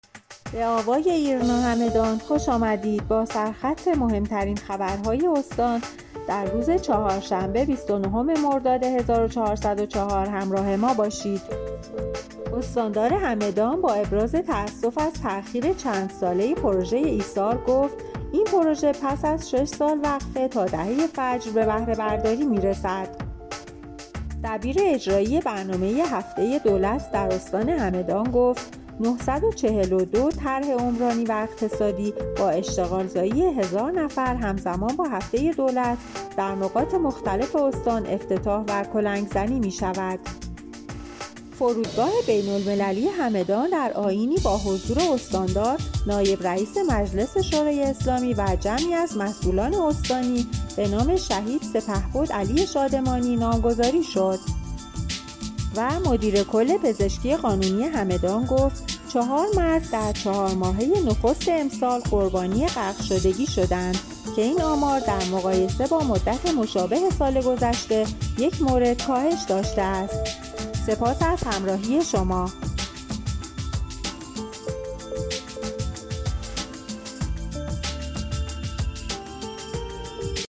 همدان- ایرنا- مهم‌ترین عناوین خبری دیار هگمتانه را هر روز از بسته خبر صوتی آوای ایرنا همدان دنبال کنید.